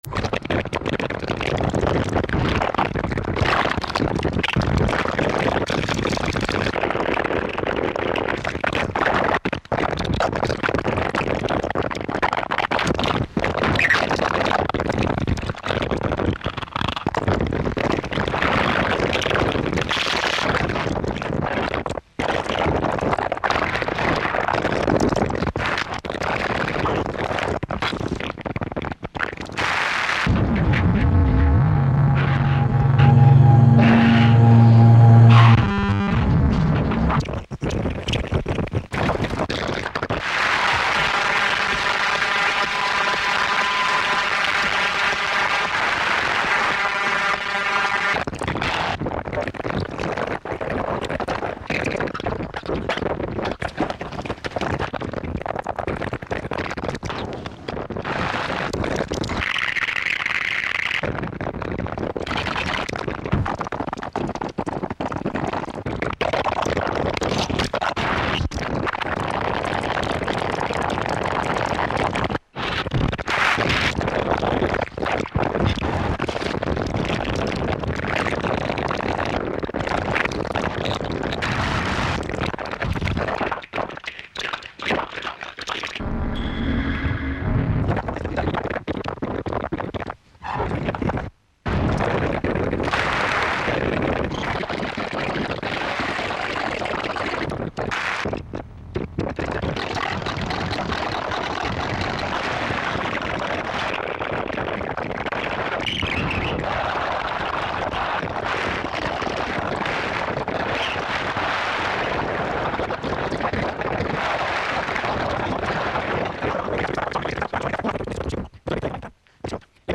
File under: Dada / Power Electronics